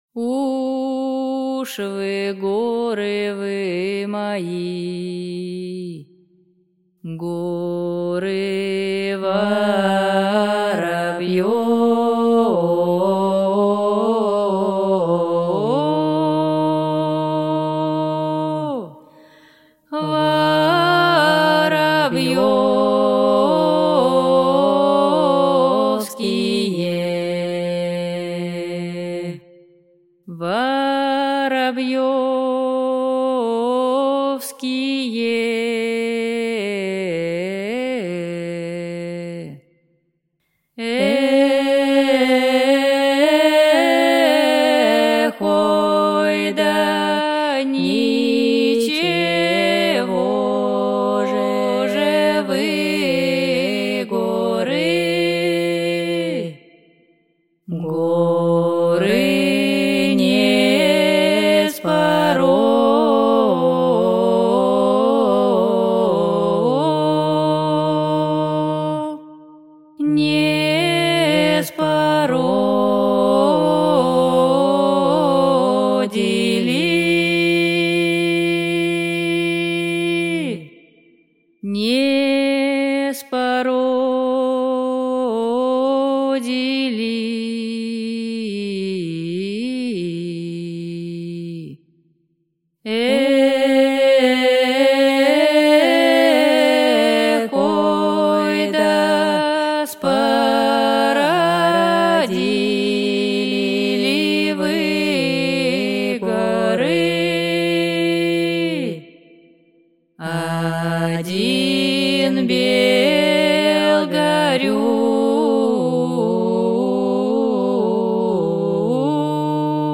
Аудиокнига Русские колыбельные | Библиотека аудиокниг